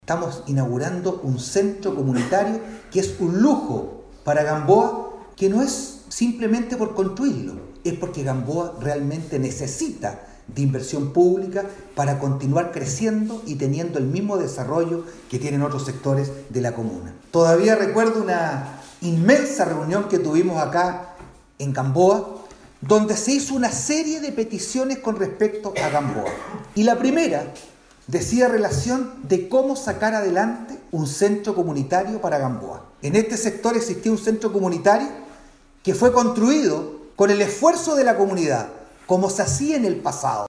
alcalde-vera-1-sede-gamboa.mp3